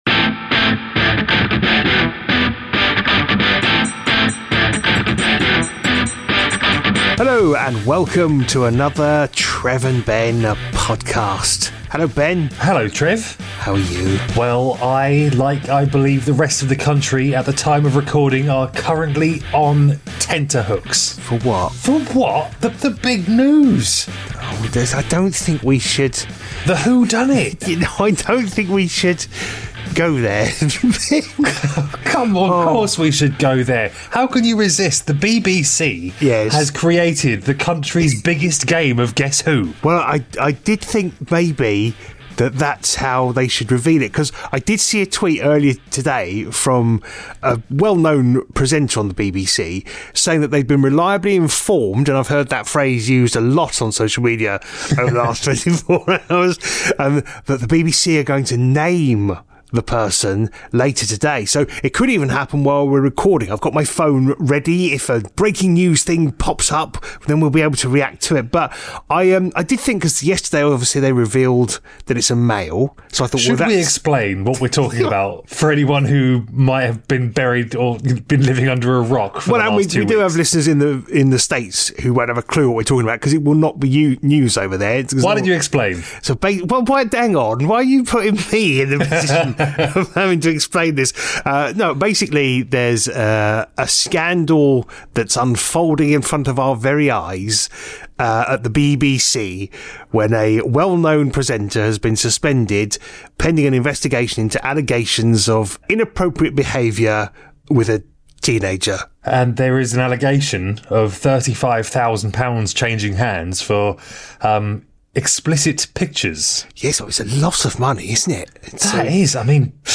This week we contemplate the UK's biggest ever game of guess who? When cricket just isn't cricket and a bit of the worst singing you've ever heard.